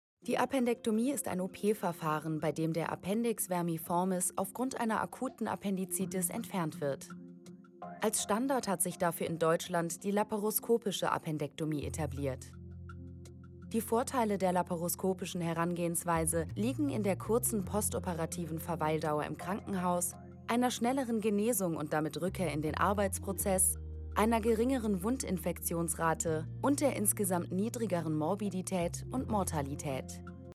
sehr variabel
Mittel minus (25-45)
Doku